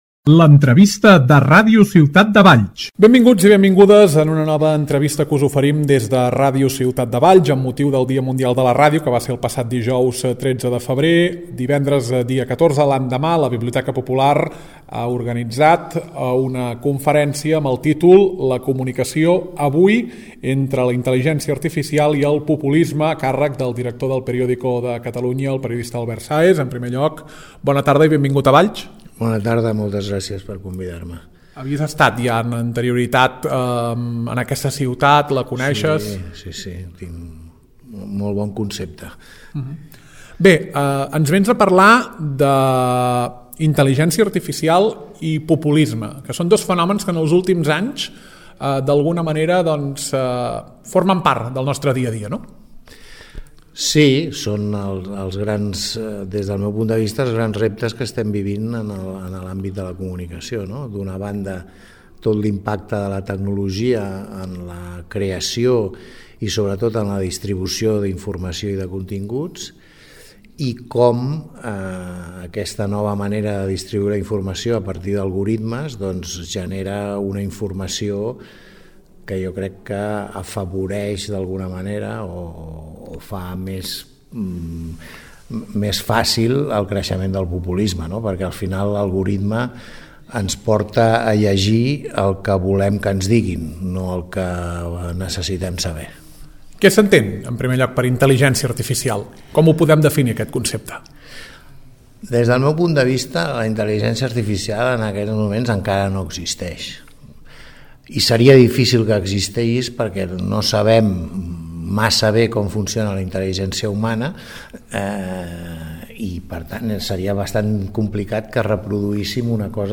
Nova entrevista a Ràdio Ciutat de Valls.